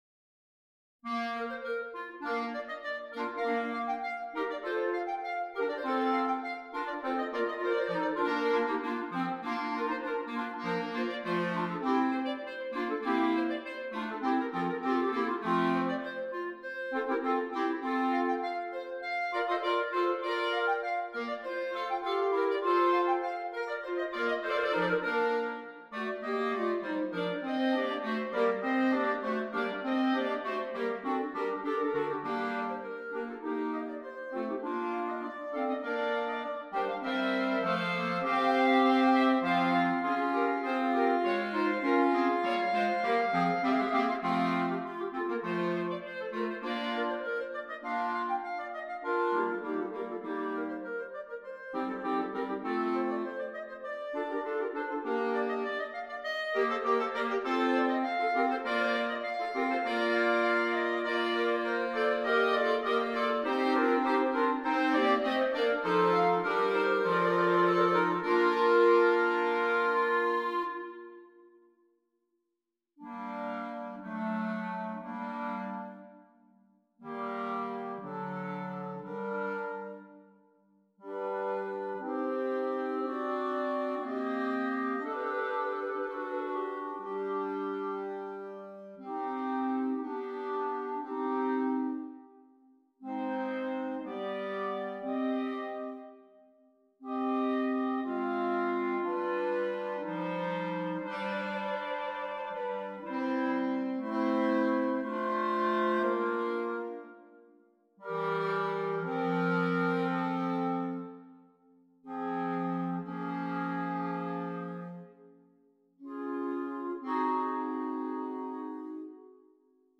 6 Clarinets, Bass Clarinet